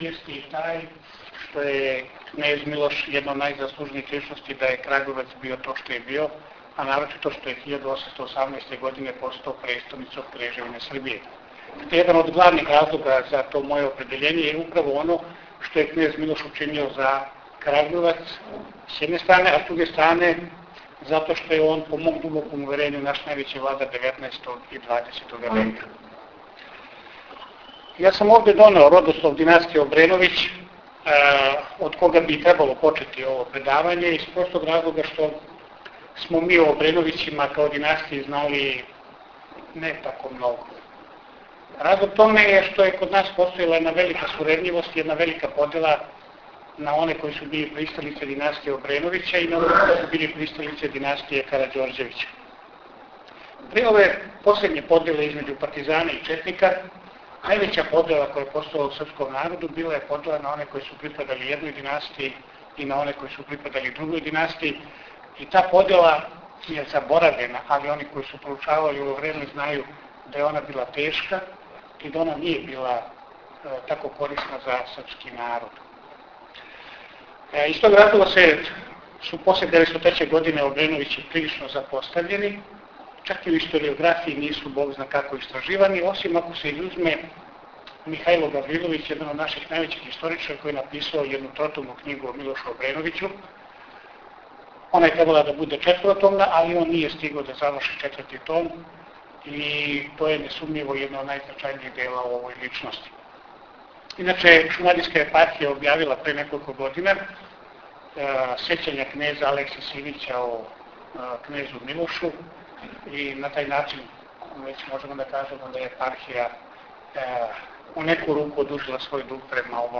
ПРЕДАВАЊЕ У ДУХОВНОМ ЦЕНТРУ У КРАГУЈЕВЦУ - Епархија Шумадијска
Након добро осмишљеног и успешно изложеног предавања, развила се жива дискузија у којој су нарочито истакнути неки занимљиви детаљи из живота кнеза Милоша.